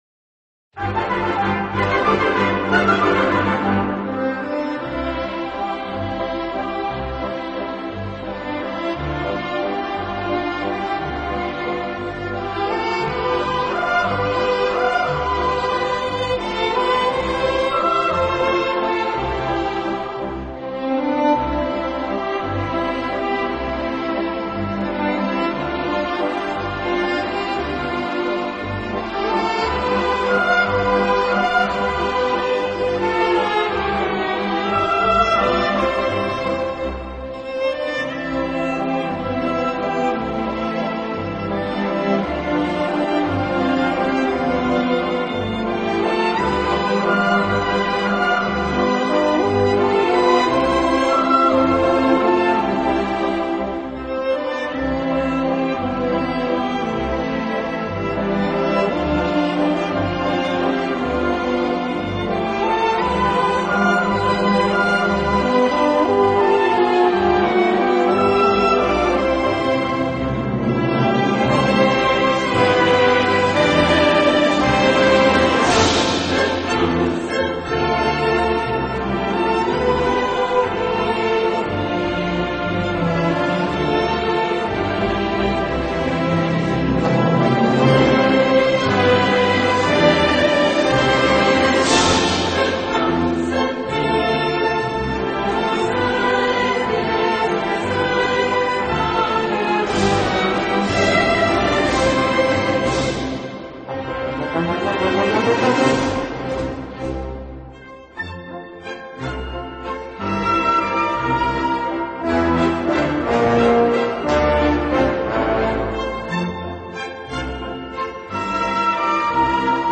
【小提琴专辑】
音乐类型：Classic 古典
音乐风格：Classical,Waltz